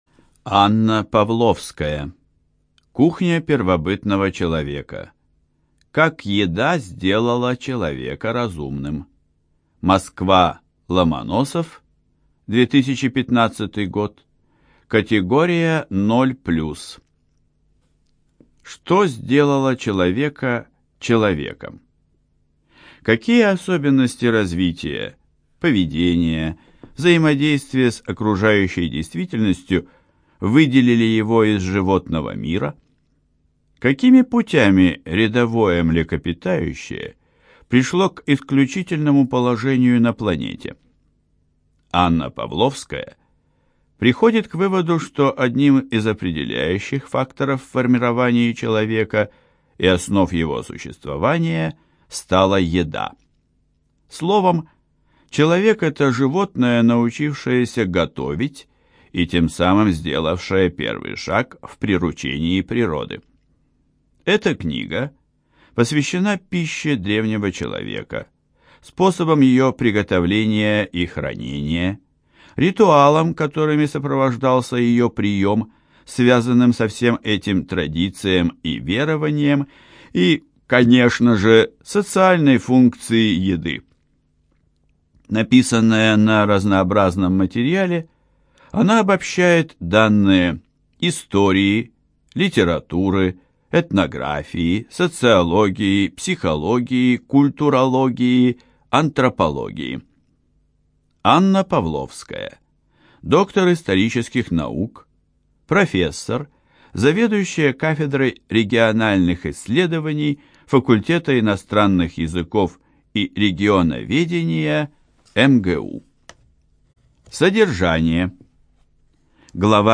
ЖанрНаучно-популярная литература
Студия звукозаписиЛогосвос